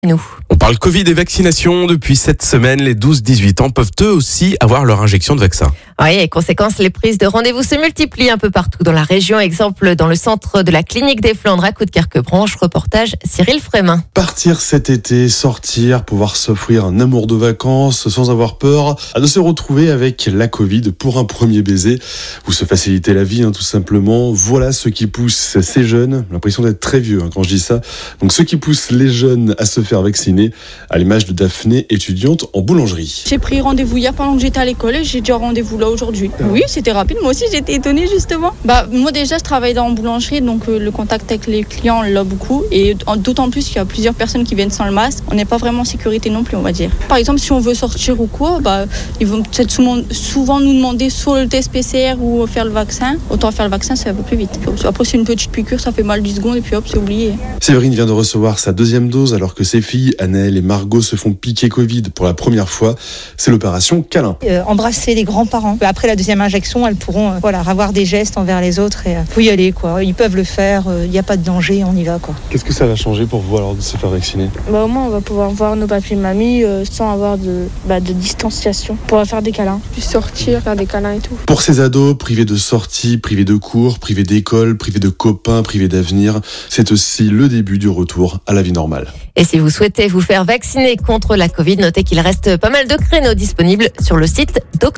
Reportage Delta FM à la Clinique de Flandre sur la vaccination des 12/18 ans